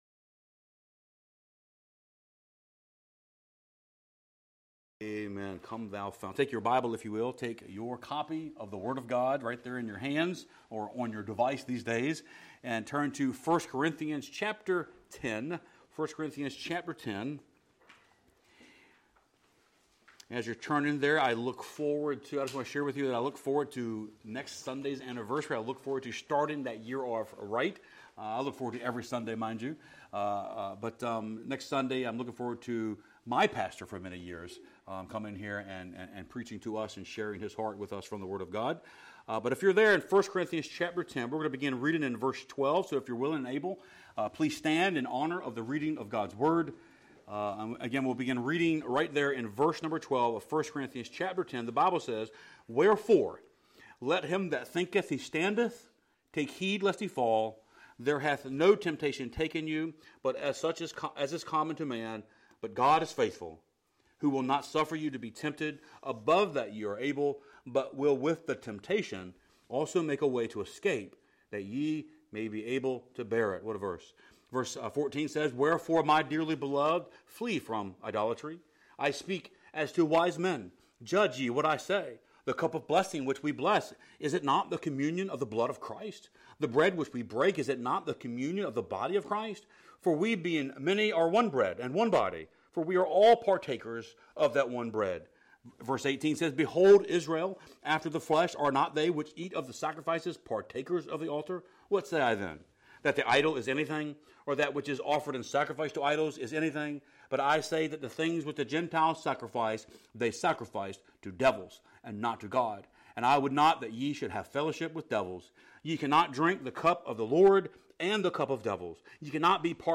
Sermons | Hohenfels Baptist Church